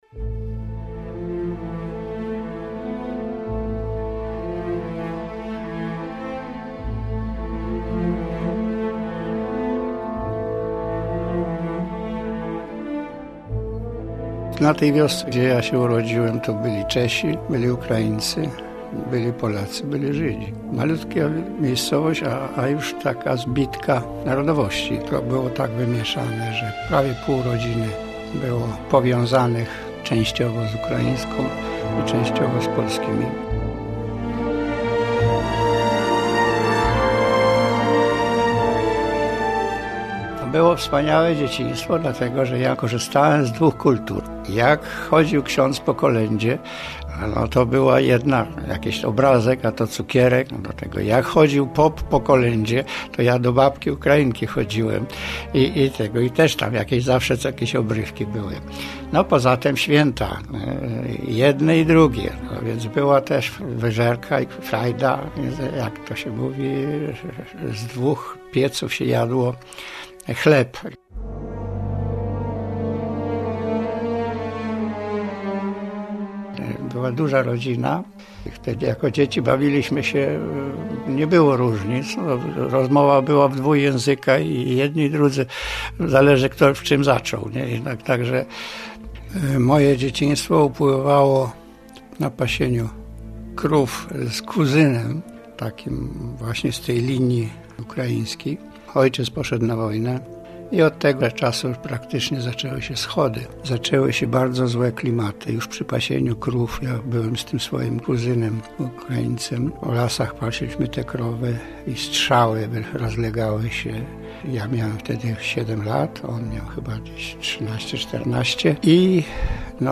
Oto jej reportaż - "Pękło serce babki Ukrainki".